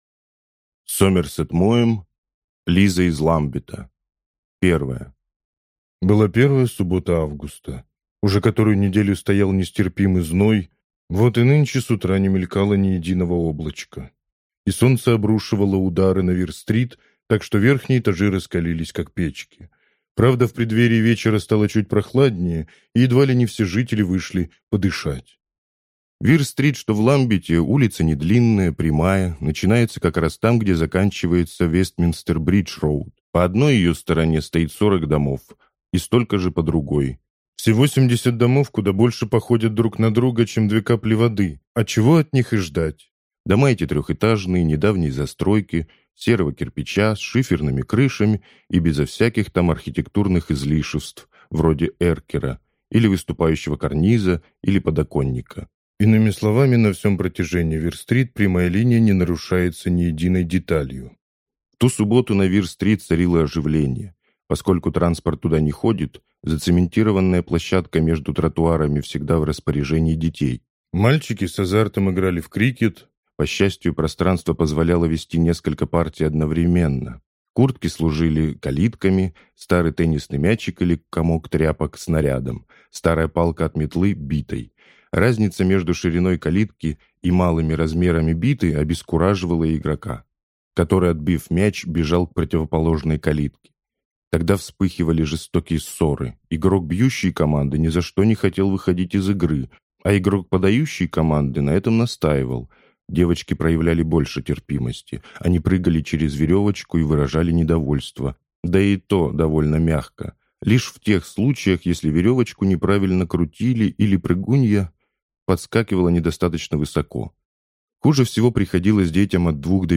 Аудиокнига Лиза из Ламбета | Библиотека аудиокниг